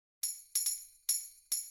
描述：用失谐的锯齿合成器演奏合成器无人机。
Tag: 140 bpm Electronic Loops Synth Loops 295.49 KB wav Key : C